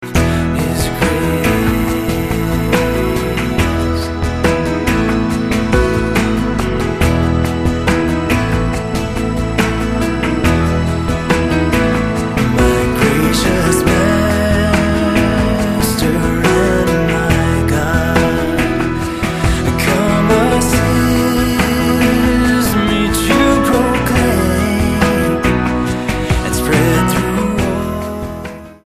STYLE: Celtic